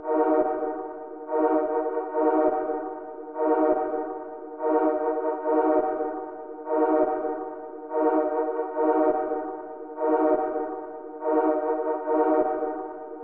蔑视这一点 垫子 145bpm
描述：陷阱和科幻的结合。沉重的打击和神秘感。
Tag: 145 bpm Trap Loops Pad Loops 2.23 MB wav Key : D